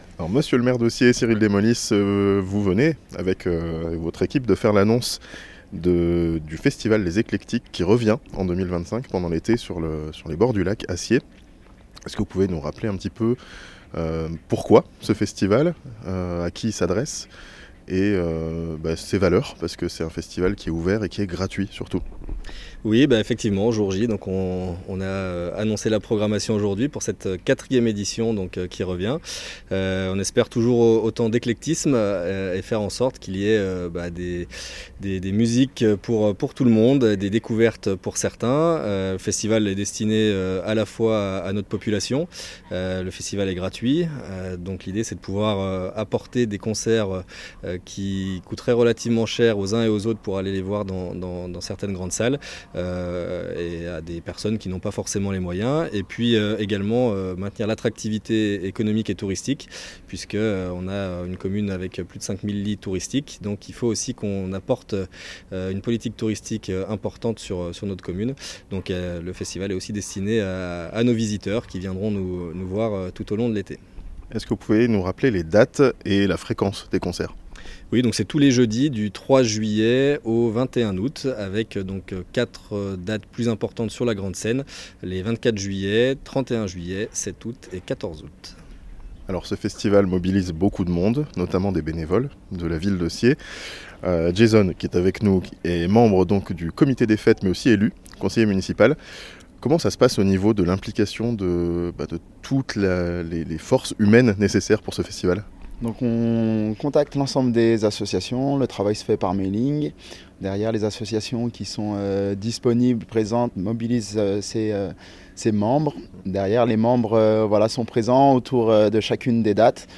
Kyo, les Ogres de Barback, la Rue Ketanou, Kadebostany seront têtes d'affiches du festival les Eclectiks, à Sciez-sur-Léman (interview)
Cyril Demolis, maire de Sciez, Jason Da Costa, membre du comité des fêtes et conseiller municipal de la ville de Sciez